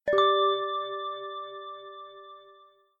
echoed-ding-459.mp3